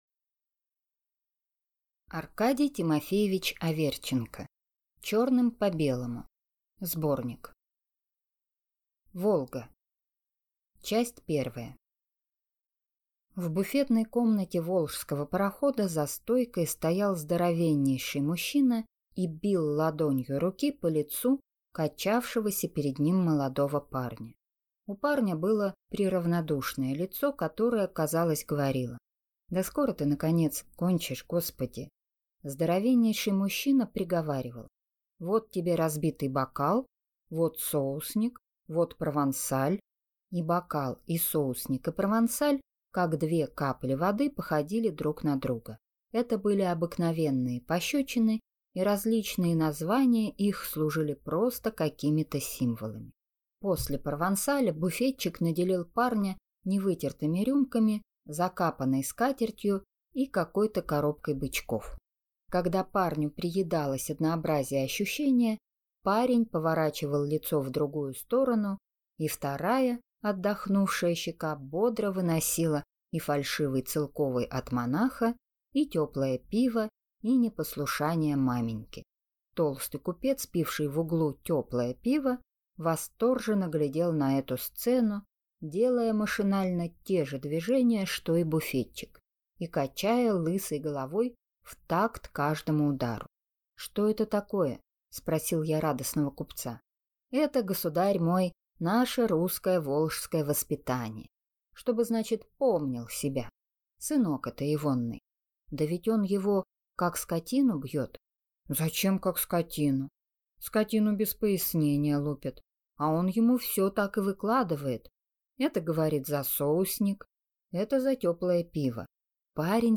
Аудиокнига Черным по белому (сборник) | Библиотека аудиокниг